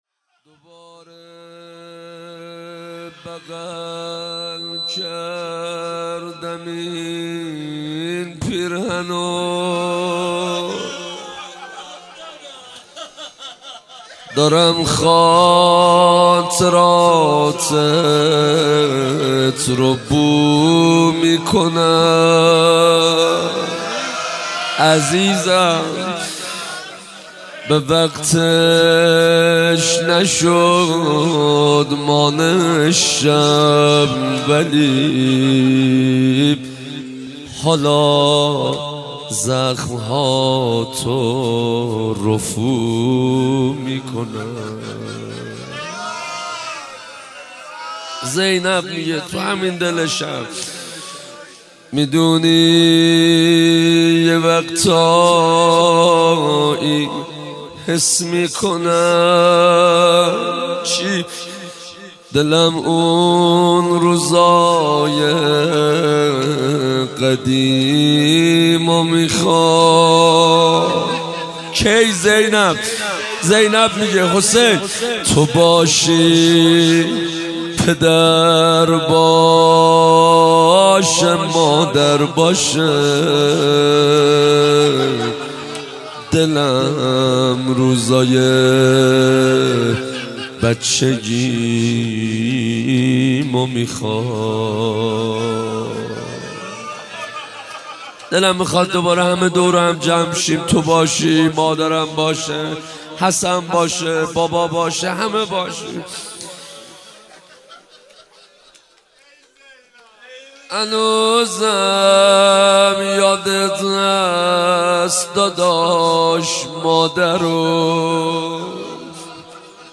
روضه حضرت زینب